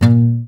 Index of /90_sSampleCDs/Sound & Vision - Gigapack I CD 2 (Roland)/GUI_ACOUST. 32MB/GUI_Acoust. Slap